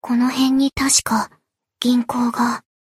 贡献 ） 分类:蔚蓝档案语音 协议:Copyright 您不可以覆盖此文件。
BA_V_Shiroko_Battle_Move_1.ogg